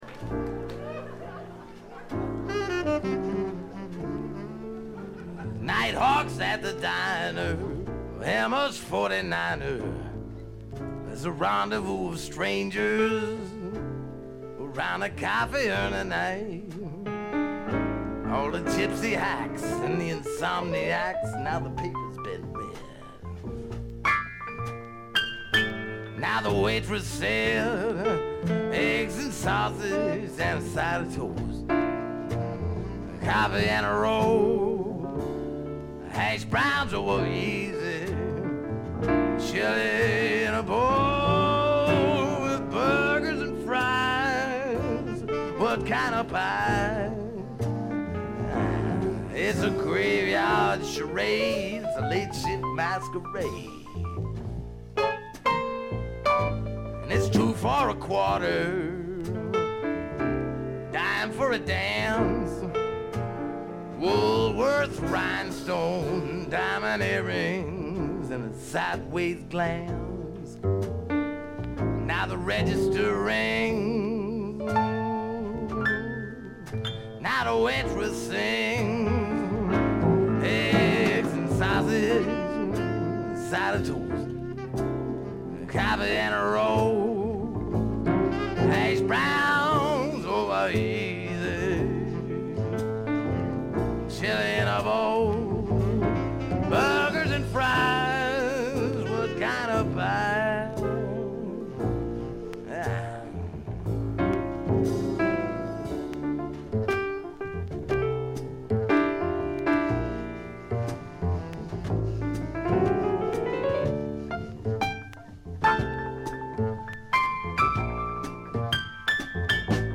部分試聴ですがわずかなノイズ感のみ。
70年代シンガー・ソングライターの時代を代表するライヴアルバムでもあります。
試聴曲は現品からの取り込み音源です。